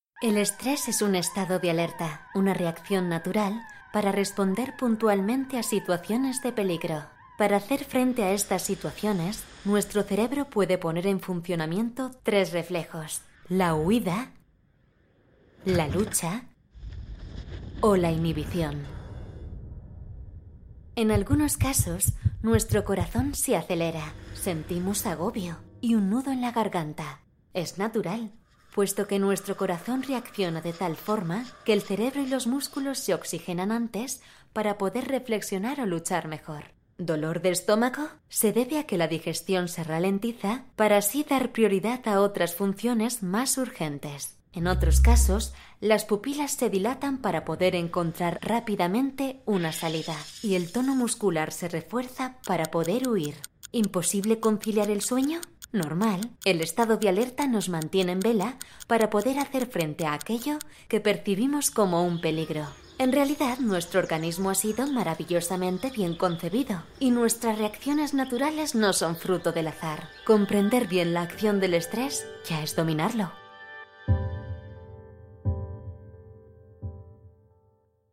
ACTRIZ DOBLAJE / LOCUTORA PUBLICITARIA / CANTANTE Voz versátil, cálida, elegante, sensual, fresca, natural, dulce, enérgica...
kastilisch
Sprechprobe: Industrie (Muttersprache):
A versatile, experienced , clear, credible and vibrant voice over artist.